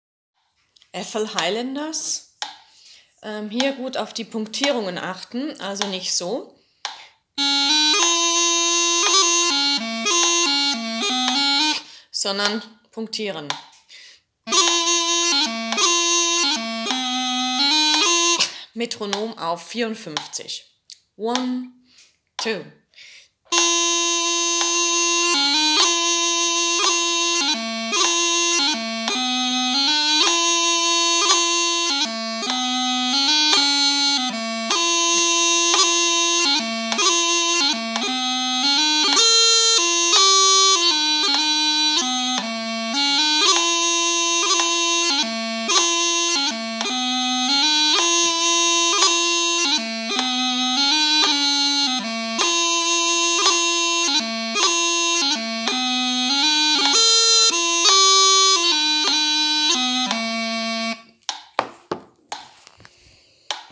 Bagpipe - Caverhill Guardians